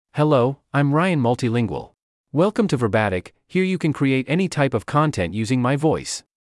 Ryan Multilingual — Male English (United States) AI Voice | TTS, Voice Cloning & Video | Verbatik AI
MaleEnglish (United States)
Ryan Multilingual is a male AI voice for English (United States).
Voice sample
Listen to Ryan Multilingual's male English voice.
Male